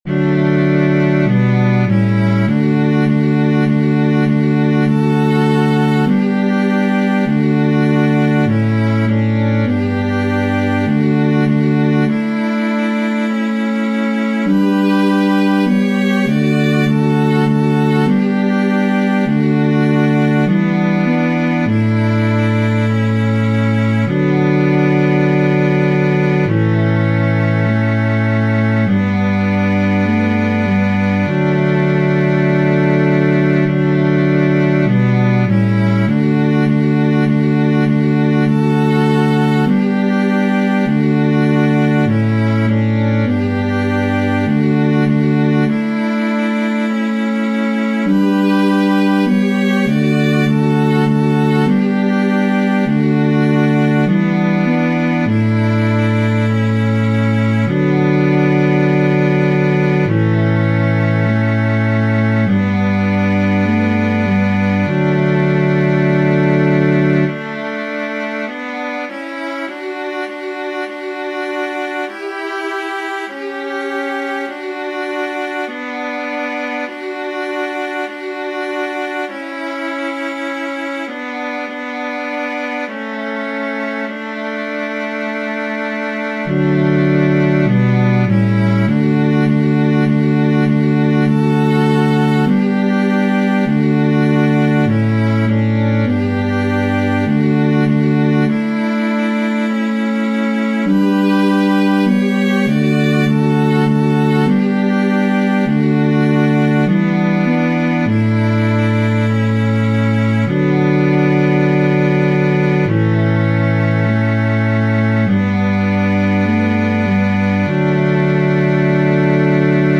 Antienne d'ouverture Téléchargé par